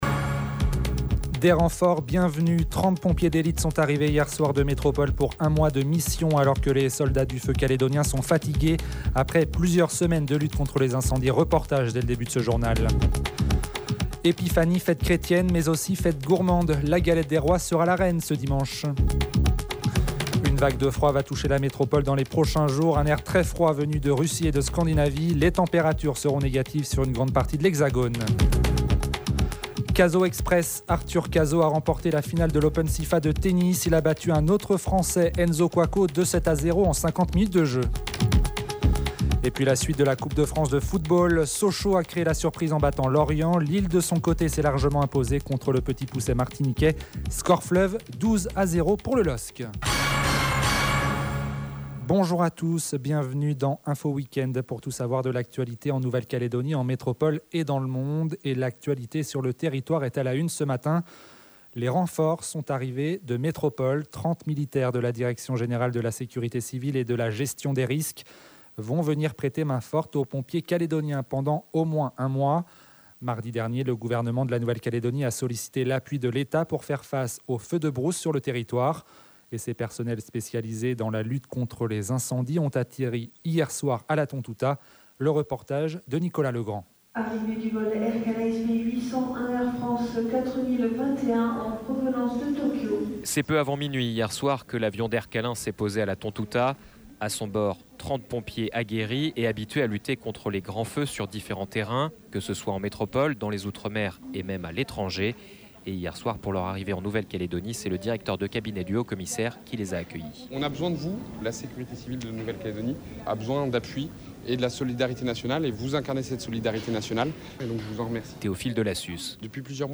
JOURNAL : INFO WEEKEND DIMANCHE MATIN